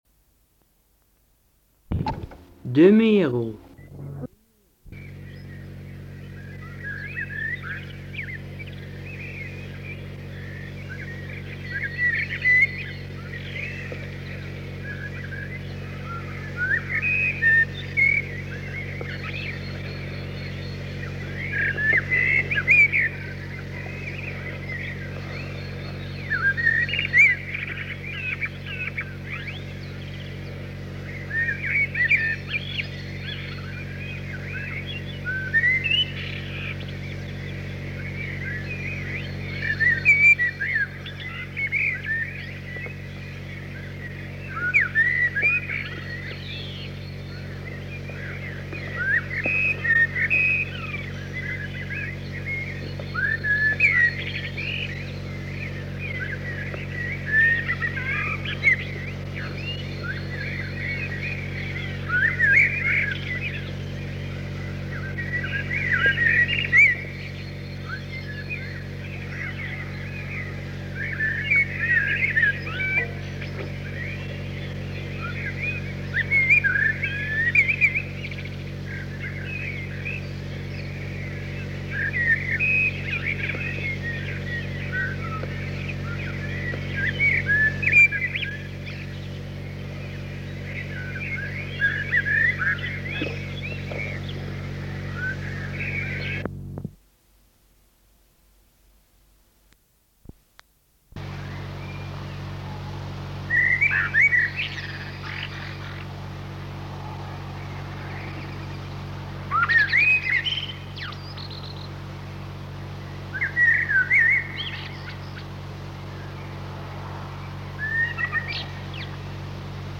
Ik heb deze direct gedigitaliseerd en zo kunnen jullie meegenieten van mijn eerste opnamen die ik als beginnend vogelaar, vaak op de vroege zondagochtend, maakte. Ik persoonlijk vind het erg leuk te horen hoe serieus ik klink wanneer ik de verschillende vogels aankondig… Onderaan deze pagina vinden jullie het volledige bandje, bij een aantal soorten heb ik de stukjes apart toegevoegd. link link Mijn SOVON verleden…